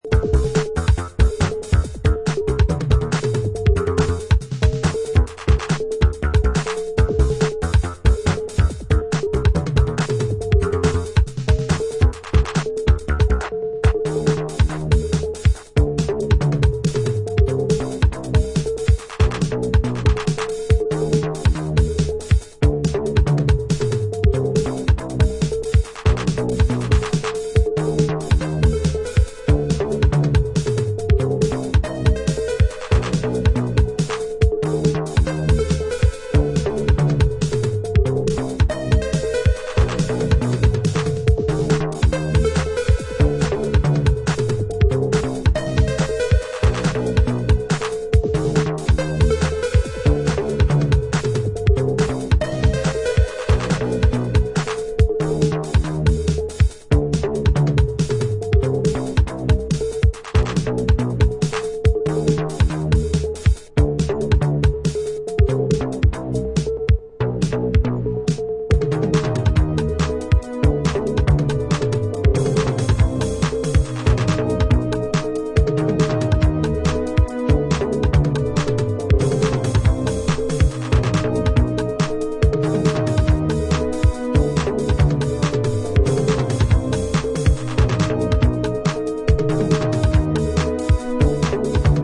New York Disco, Chicago House and Detroit Techno